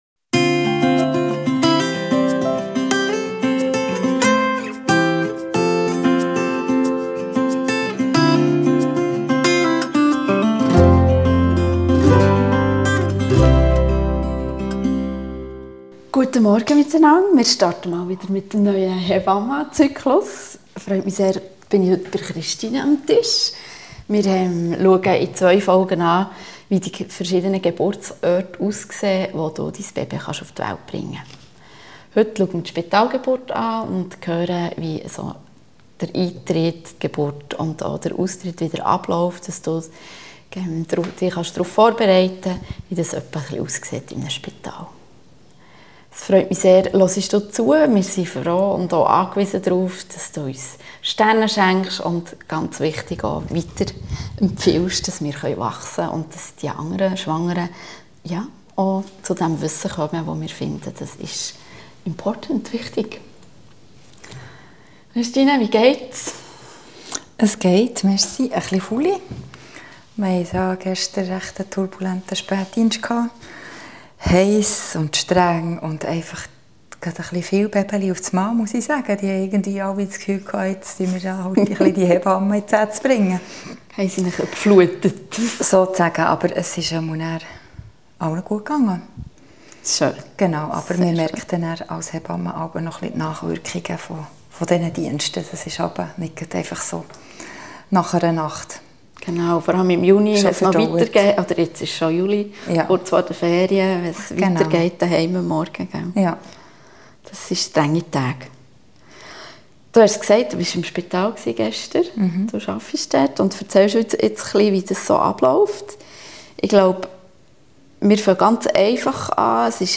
Eure Hebammen